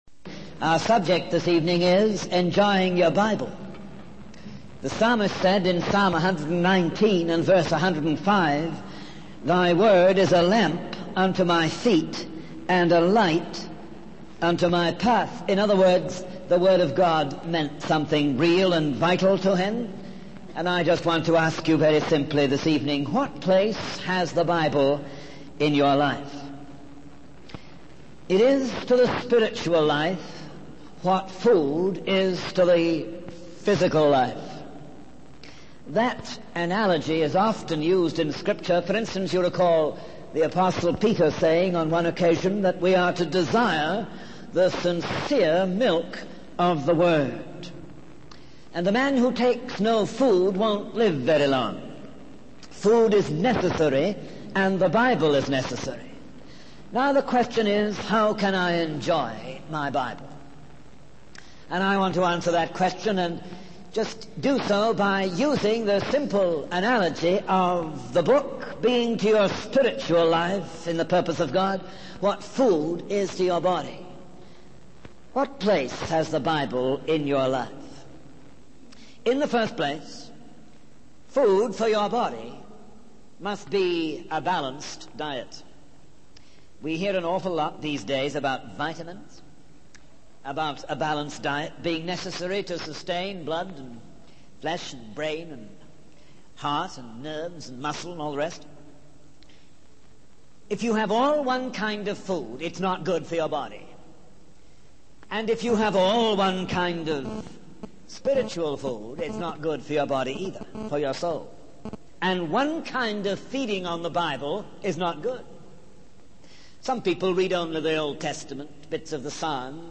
In this sermon, the preacher emphasizes the importance of admitting the word of God into our minds and lives. He encourages listeners to let the word of God soak in, take root, and become a part of their character. The preacher also emphasizes the need to submit to the authority of the word of God in daily living, making it the final word in all decisions.